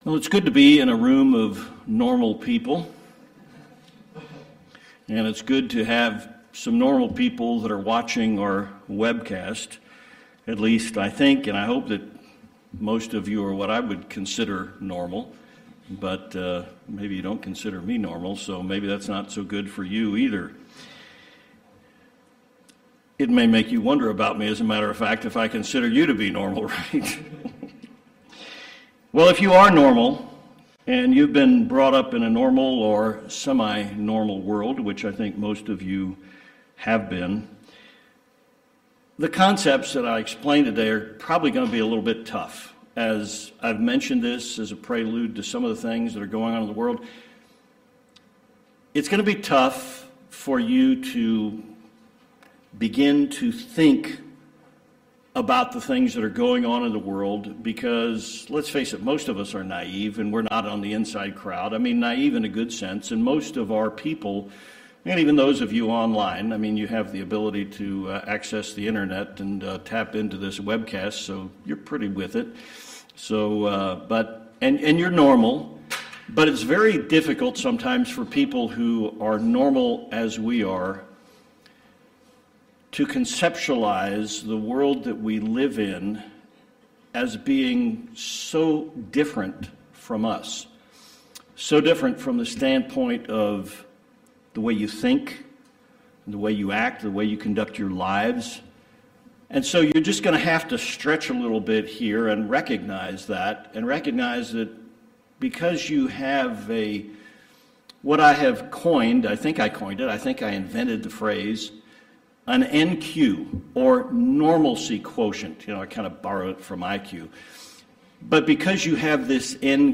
This sermon explores the history of an ancient event and its effect on our present world.